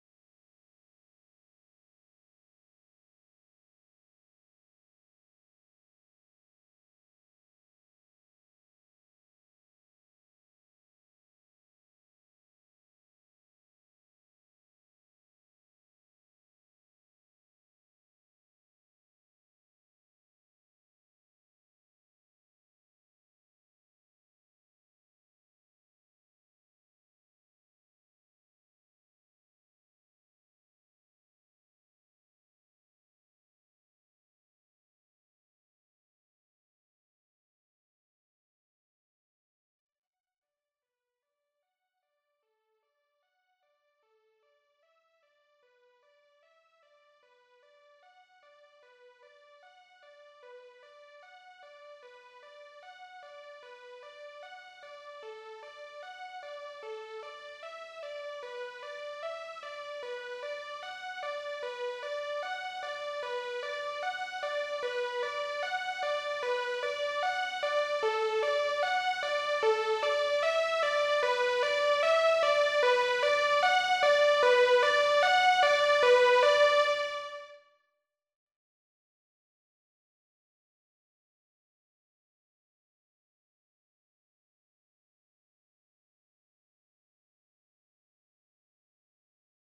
🔹 50 Custom Serum Presets crafted for wave pop, ambient electronica, and deep emotional beats.
These presets feel like water: fluid, lush, and endlessly immersive.
Emotional Leads – Soaring and delicate with cinematic movement